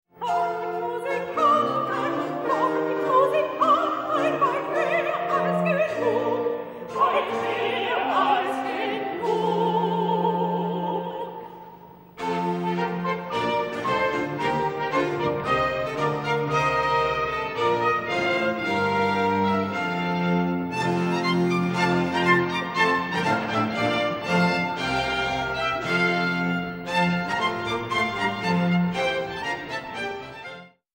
Ö1 (rec. 28.10.1997 Wr. Redoutensaal)